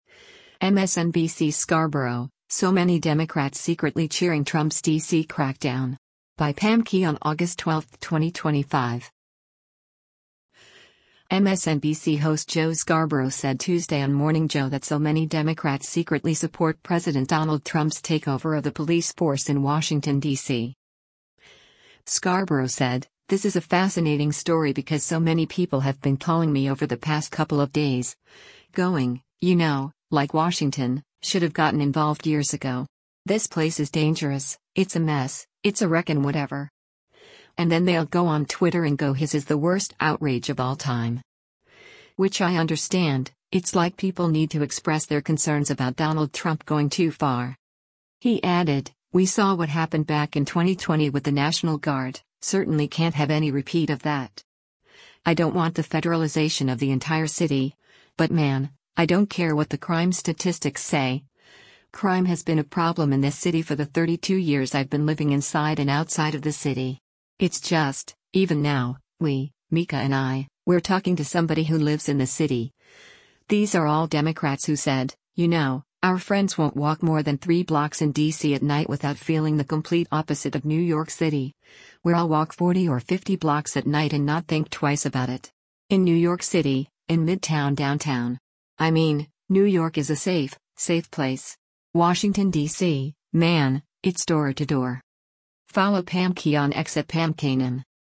MSNBC host Joe Scarborough said Tuesday on “Morning Joe” that “so many” Democrats secretly support President Donald Trump’s takeover of the police force in Washington, D.C.